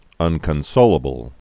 (ŭnkən-sōlə-bəl)